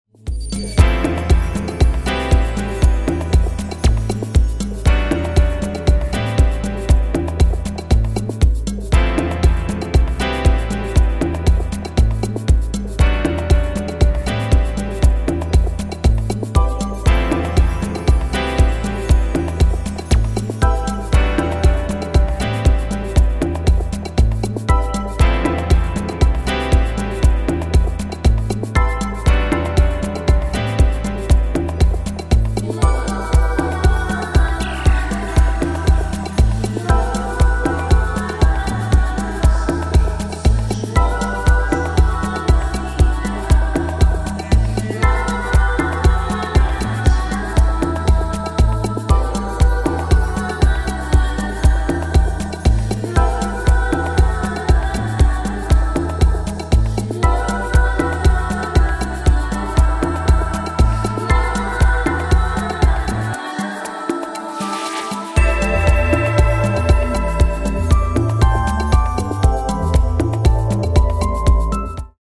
two dreamy house numbers plus ethereal remixes
Mostly instrumentals, bathed in a warm, uplifting glow.